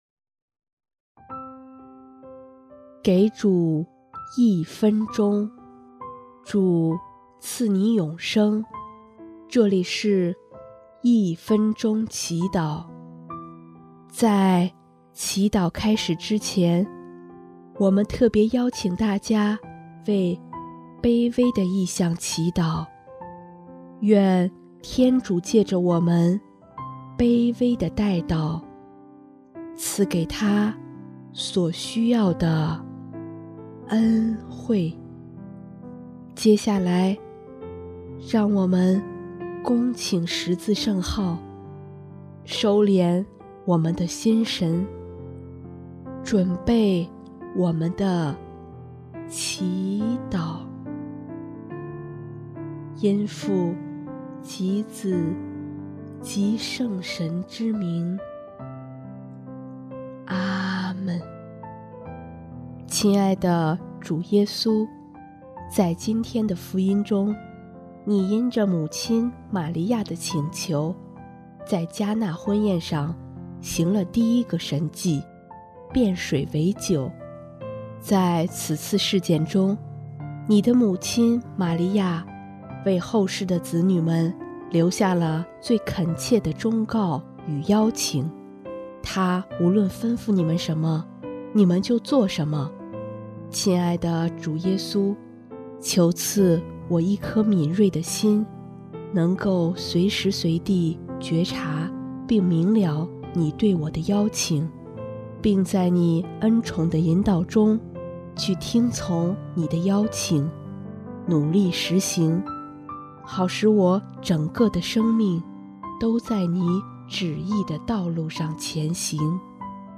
【一分钟祈祷】|1月4日 做顺服天主旨意的子民